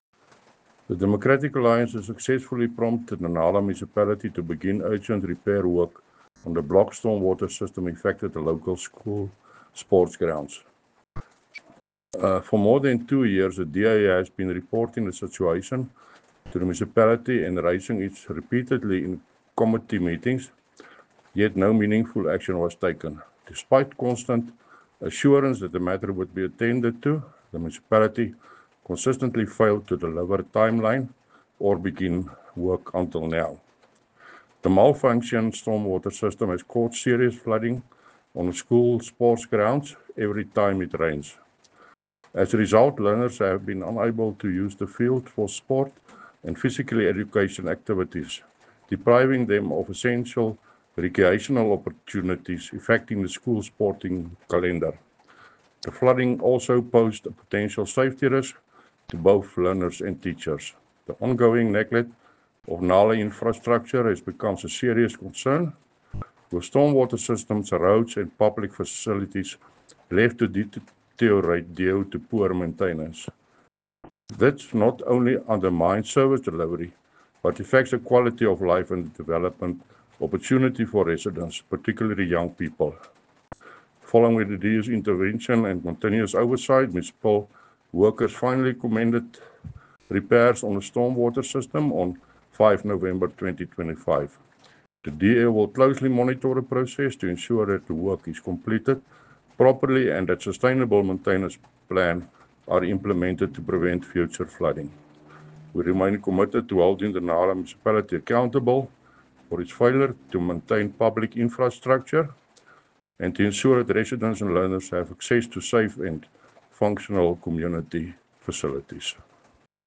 Afrikaans soundbites by Cllr Thinus Barnard and Sesotho soundbite by Cllr Kabelo Moreeng.